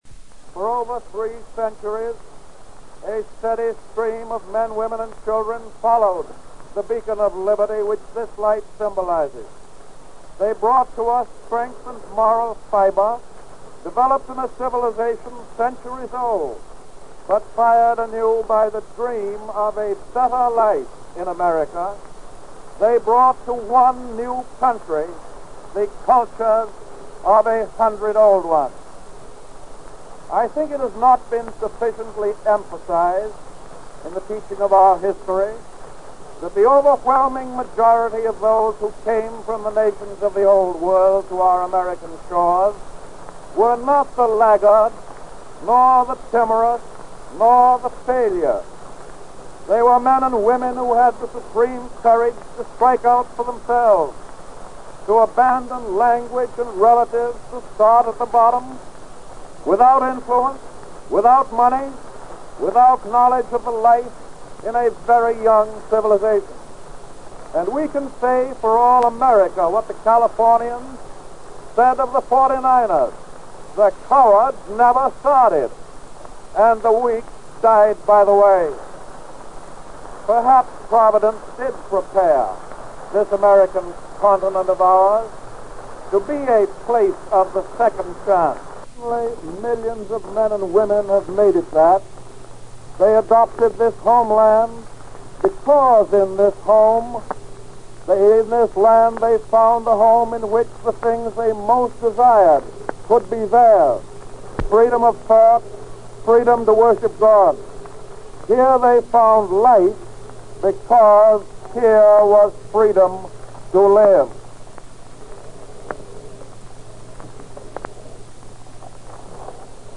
U.S. President Franklin D. Roosevelt speaks on the Statue of Liberty's 50th anniversary